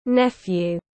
Cháu trai tiếng anh gọi là nephew, phiên âm tiếng anh đọc là /ˈnef.juː/.